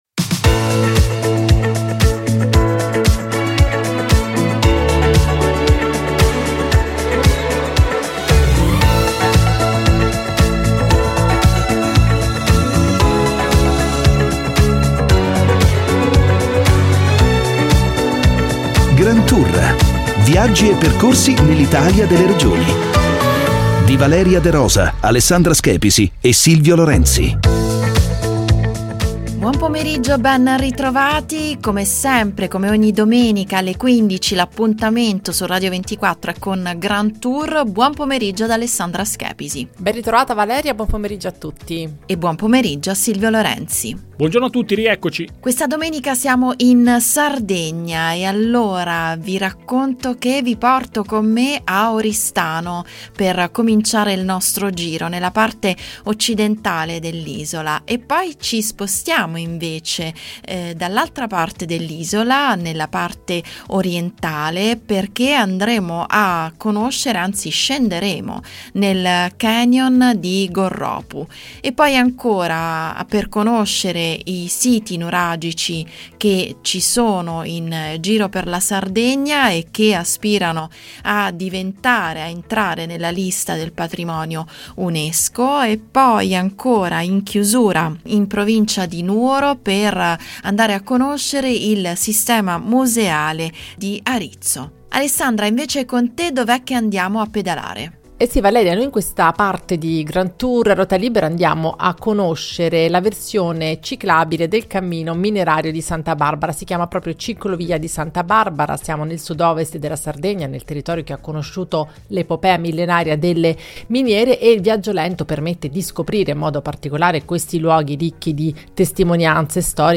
1 Mash-Up di lunedì 22/04/2024 53:08 Play Pause 4d ago 53:08 Play Pause Riproduci in seguito Riproduci in seguito Liste Like Like aggiunto 53:08 Mash Up è un dj set di musiche e parole diverse, anzi diversissime, che si confondono fra loro all’insegna di un tema diverso.